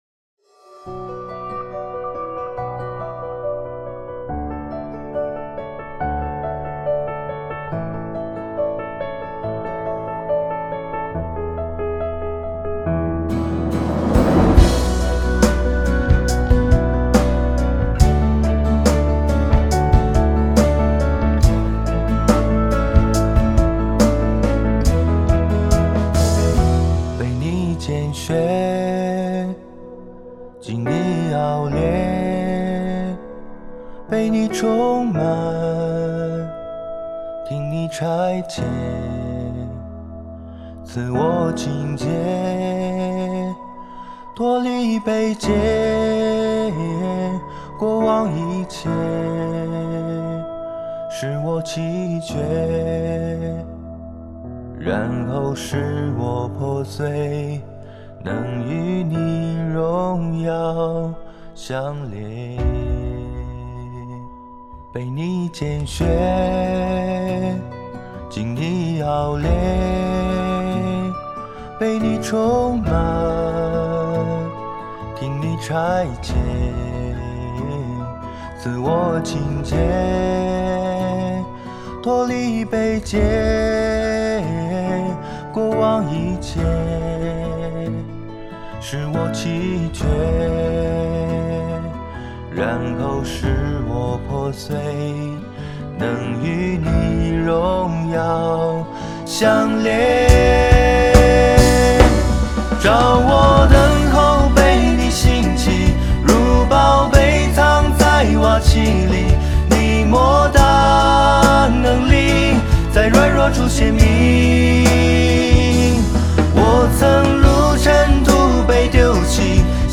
原唱音樂